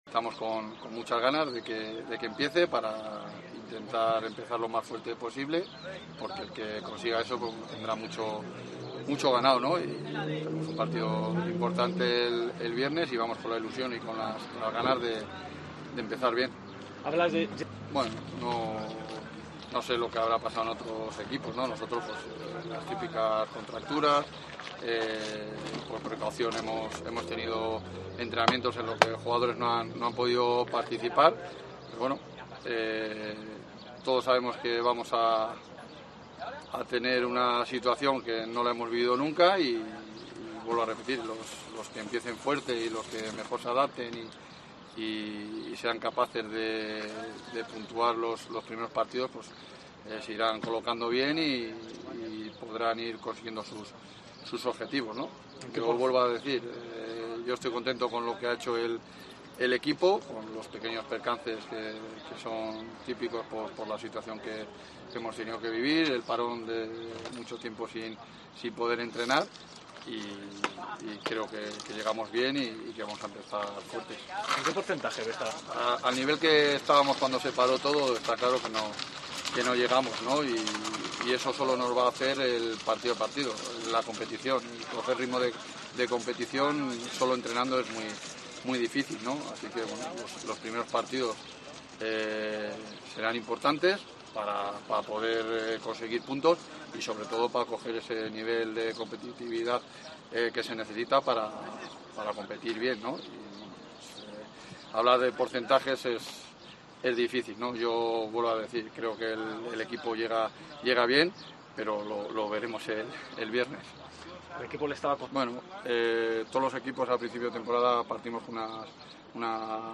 Escucha aquí las palabras del míster de la Deportiva Ponferradina, Jon Pérez Bolo